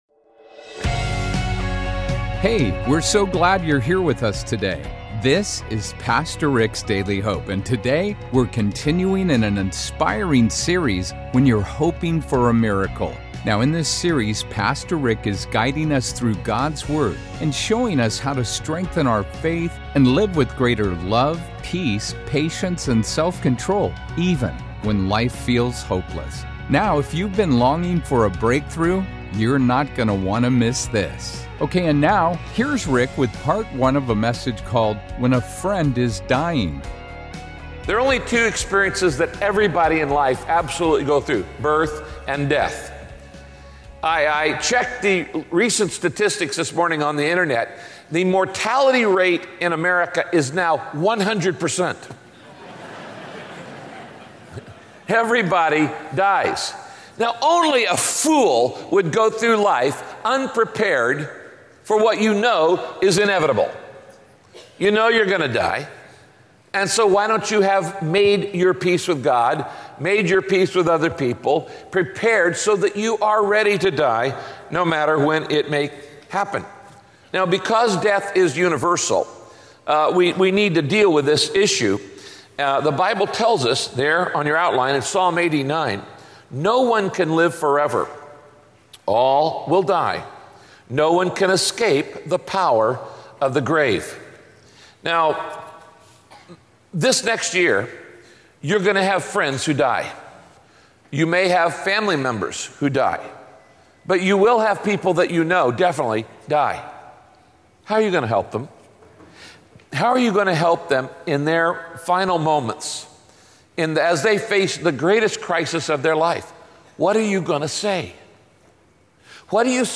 In this message, Pastor Rick teaches the importance of facing your own fears about death so you can help others who are also facing it.